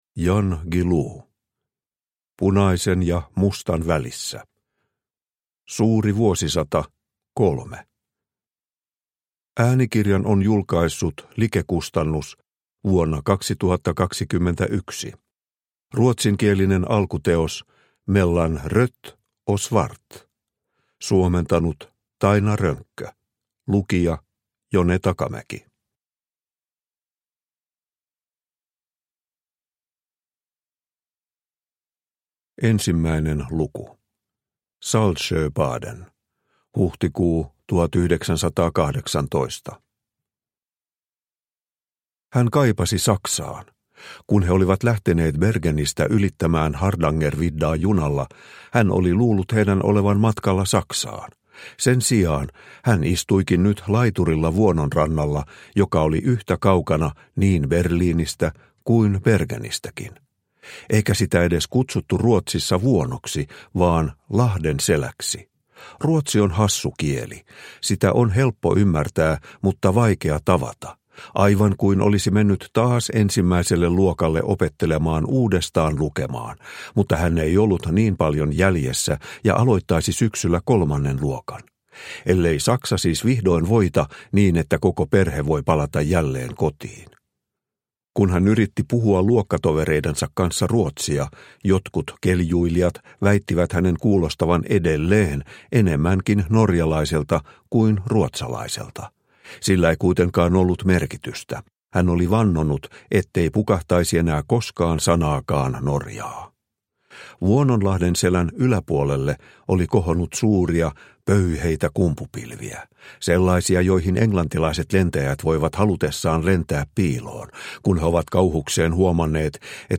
Punaisen ja mustan välissä – Ljudbok – Laddas ner